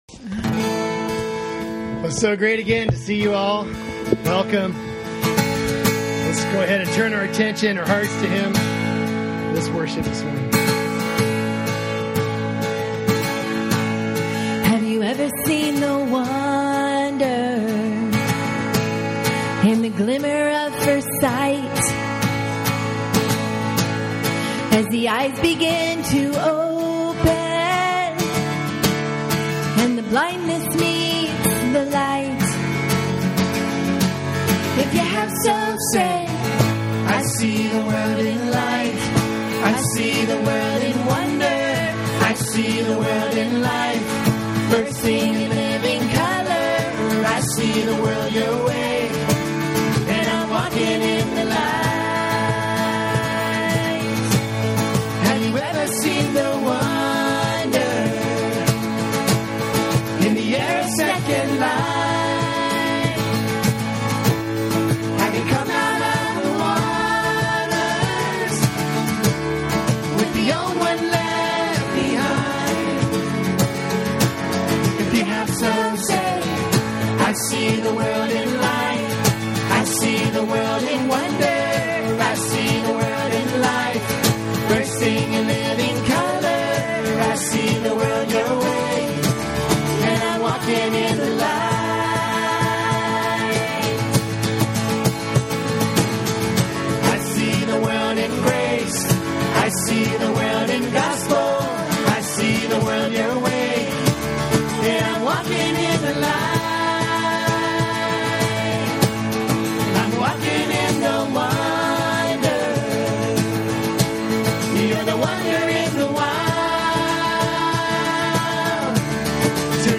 Sermons Archive - Page 2 of 64 -